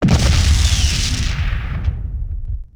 EnemyMissile.wav